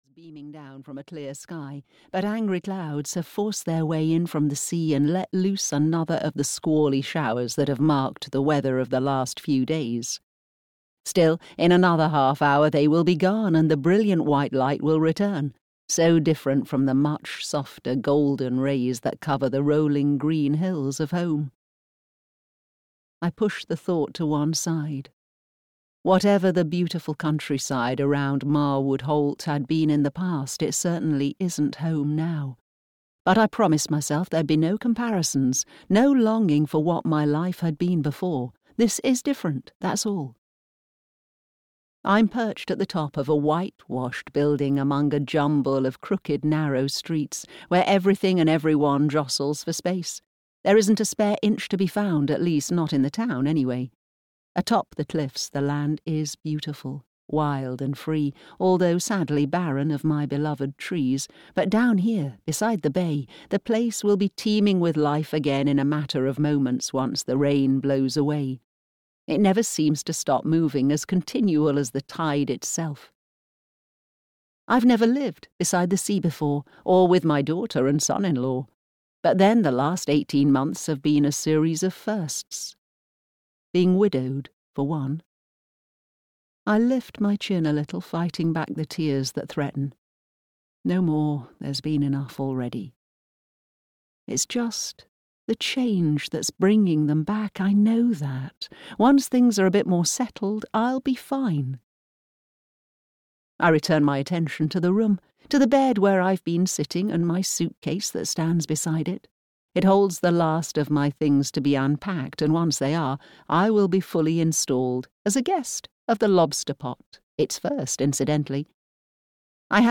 After the Crash (EN) audiokniha
Ukázka z knihy